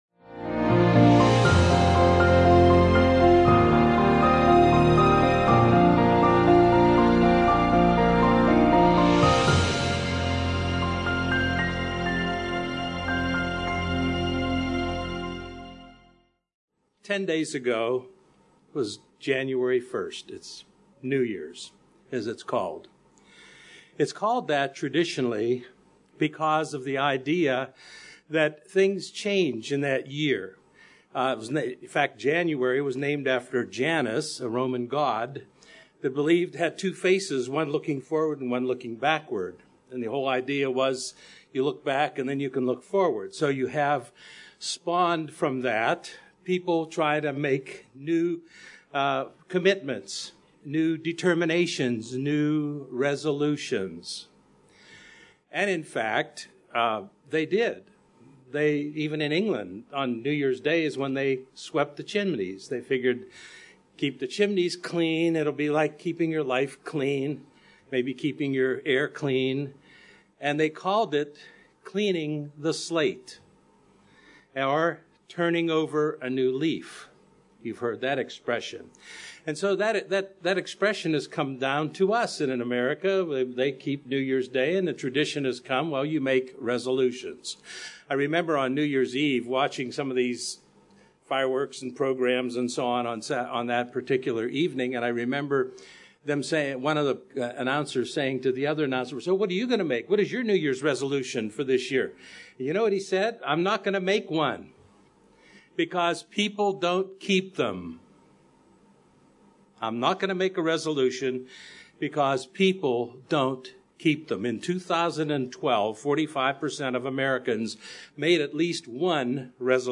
Resolutions You Need to Make | United Church of God